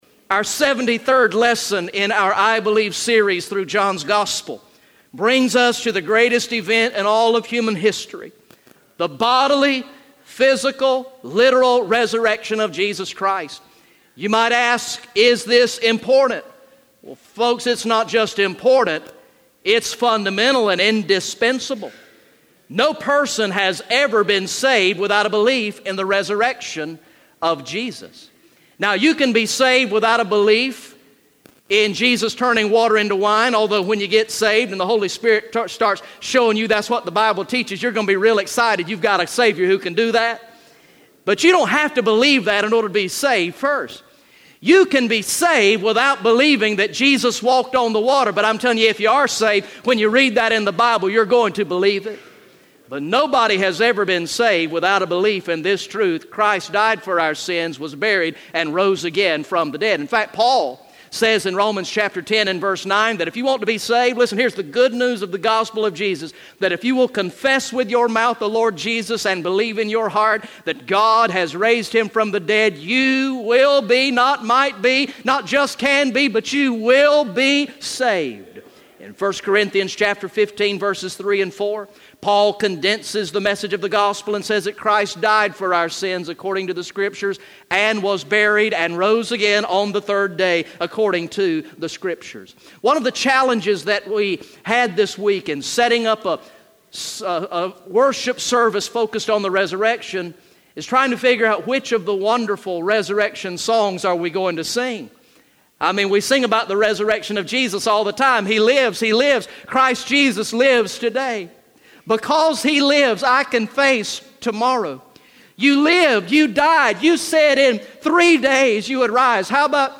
Message #73 from the sermon series through the gospel of John entitled "I Believe" Recorded in the morning worship service on Sunday, September 25, 2016